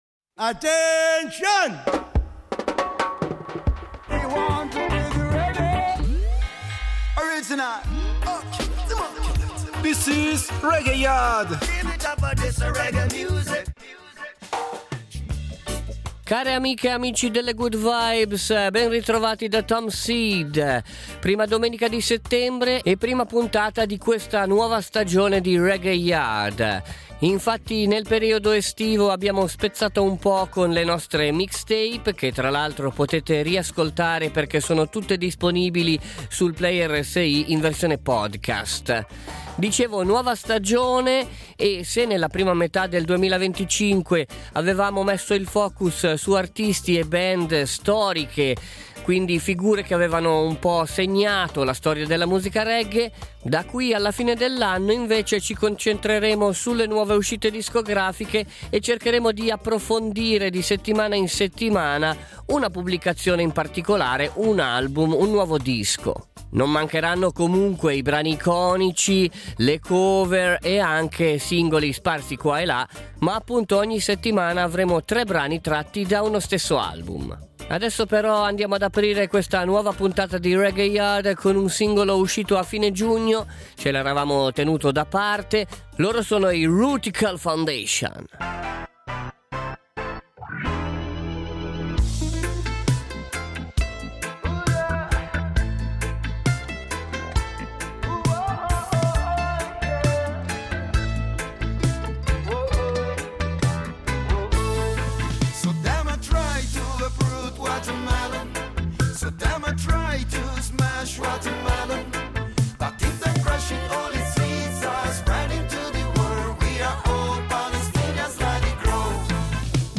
REGGAE / WORLD